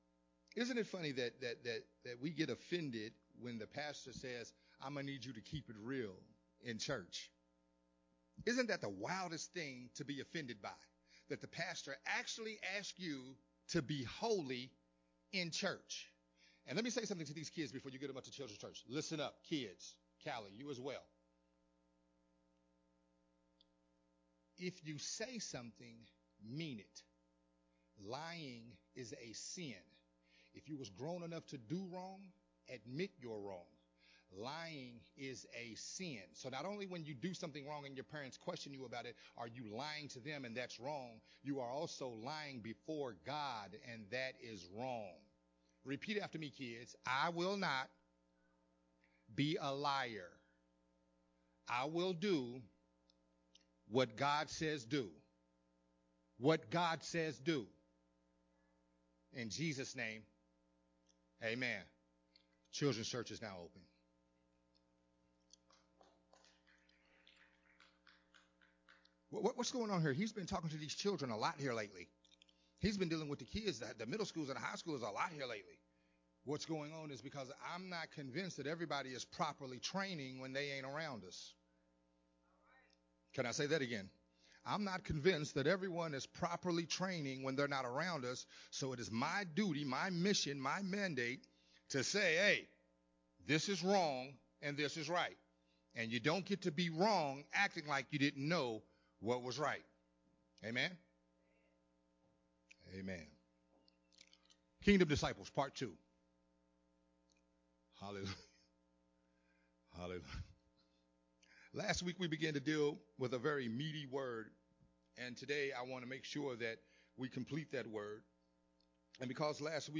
Part 2 of the sermon series, “Kingdom Disciples”
recorded at Unity Worhip Center on October 3rd, 2021.